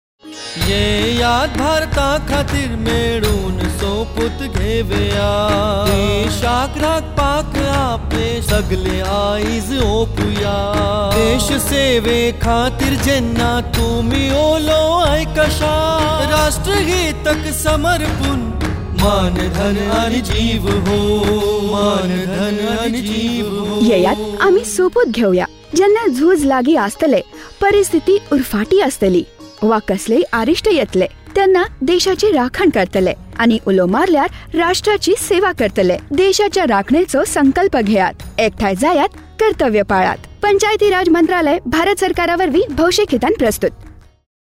105 Fundamental Duty 4th Fundamental Duty Defend the country and render national services when called upon Radio Jingle Konkani